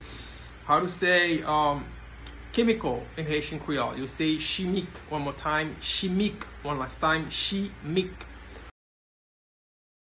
Pronunciation:
Chemical-in-Haitian-Creole-Chimik-pronunciation-by-a-Haitian-teacher.mp3